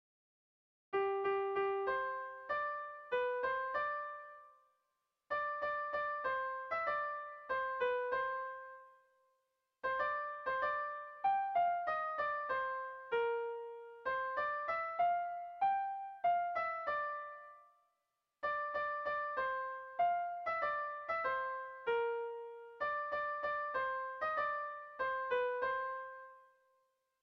Kopla handiaren moldekoa
ABDEB